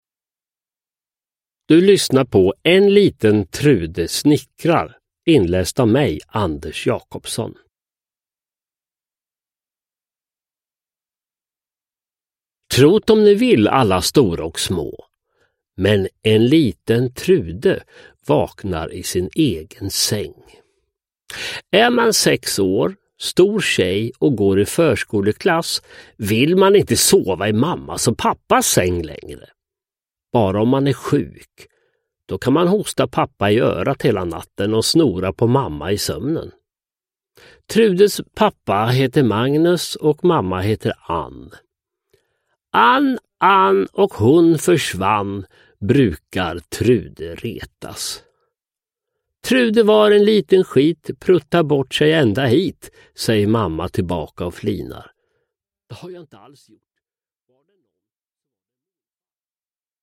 En liten Trude snickrar – Ljudbok – Laddas ner
Uppläsare: Sören Olsson, Anders Jacobsson